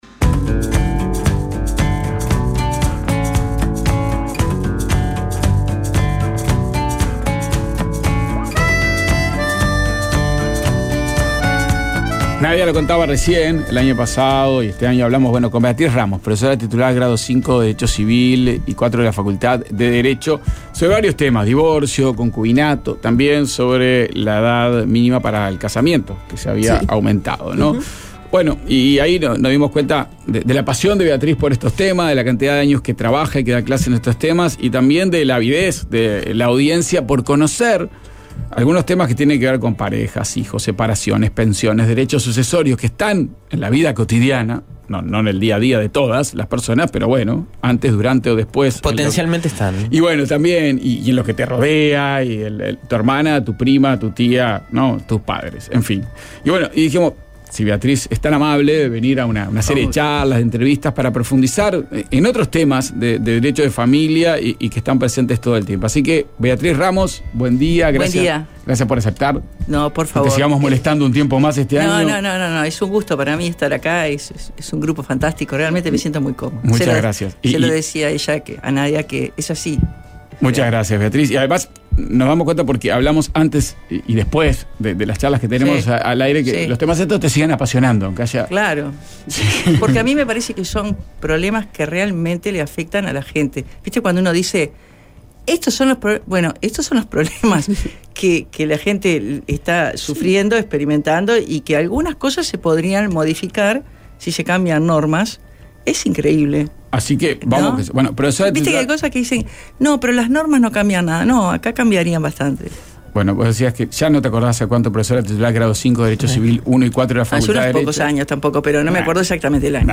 Entrevista y música en vivo.